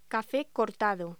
Locución: Café cortado